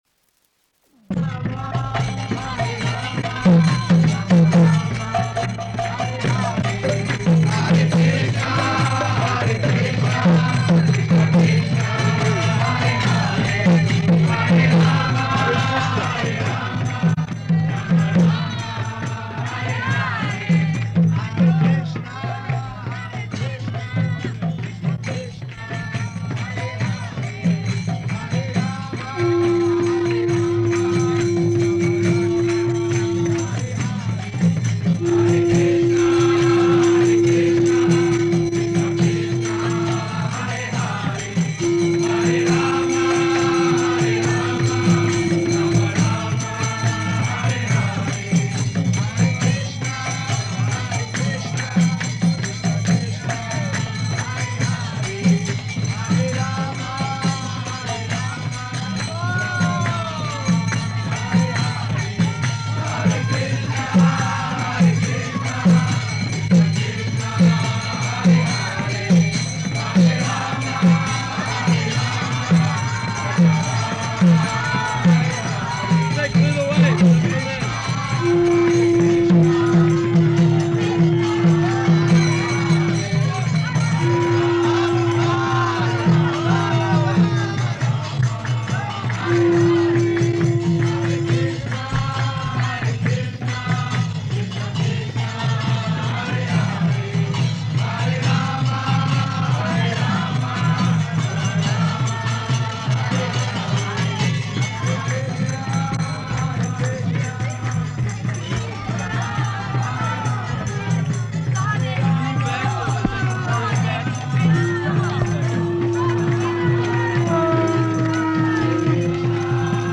Ratha-yātrā Address and Press Conference
Ratha-yātrā Address and Press Conference --:-- --:-- Type: Lectures and Addresses Dated: July 5th 1970 Location: San Francisco Audio file: 700705AD-SAN_FRANCISCO.mp3 [ kīrtana, prema-dhvanī ] Prabhupāda: Hare Kṛṣṇa.